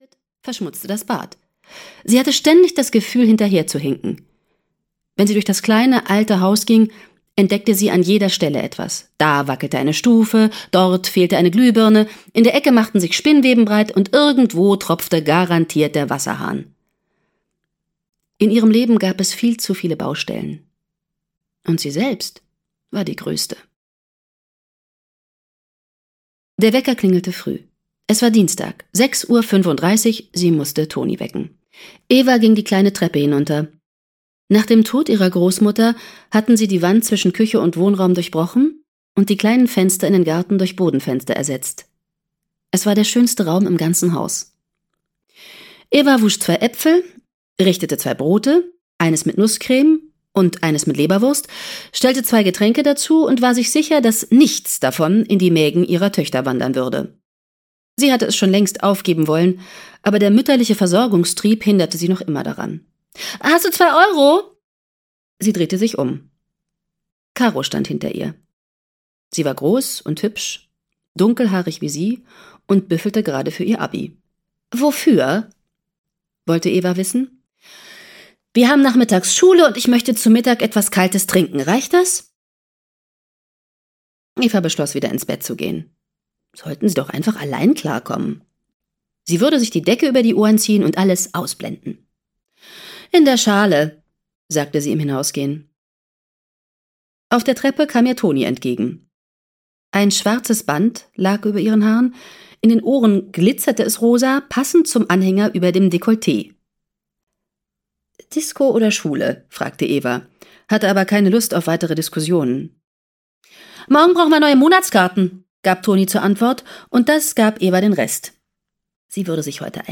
Ran an den Mann - Gaby Hauptmann - Hörbuch